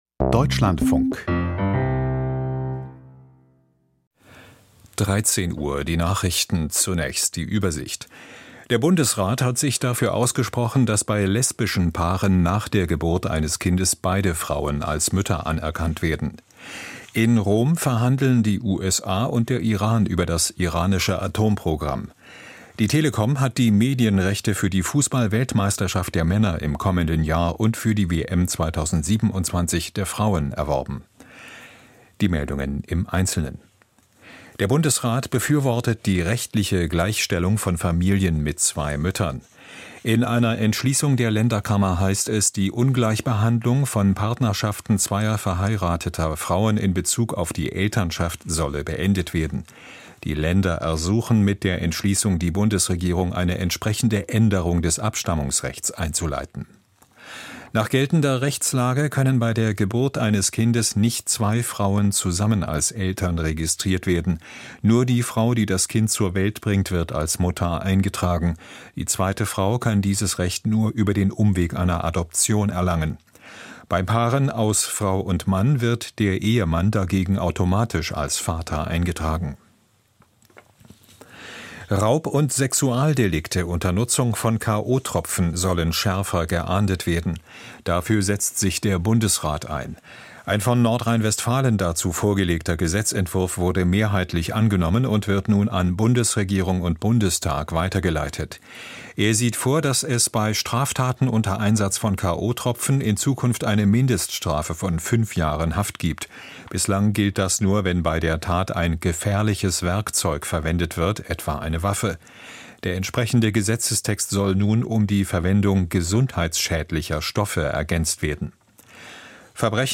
Die Nachrichten vom 23.05.2025, 13:00 Uhr
Aus der Deutschlandfunk-Nachrichtenredaktion.